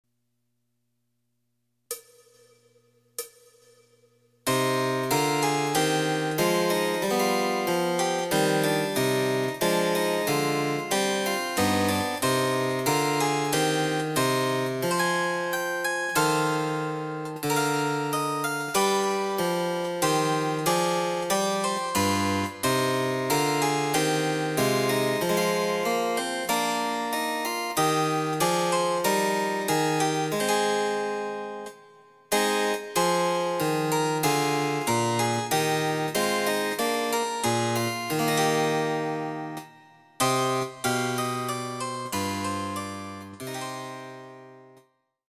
その他の伴奏
第３楽章　やや遅い
Electoric Harpsichord